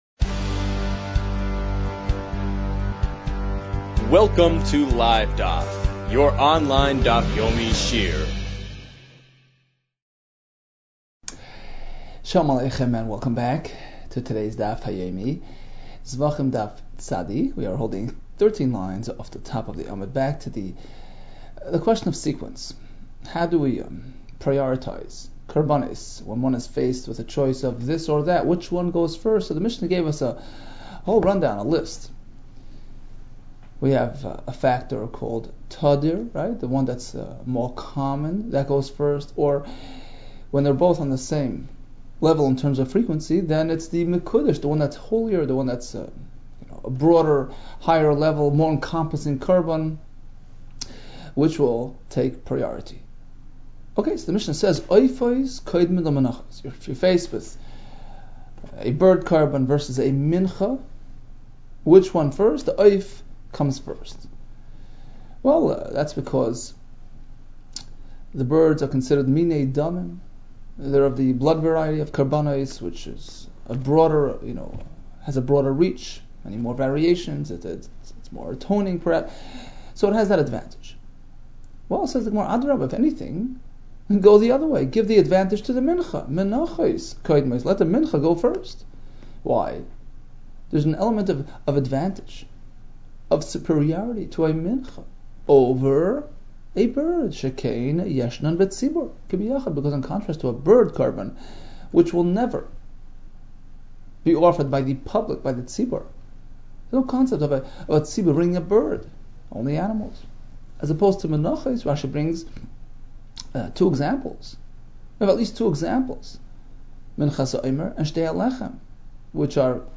Zevachim 89 - זבחים פט | Daf Yomi Online Shiur | Livedaf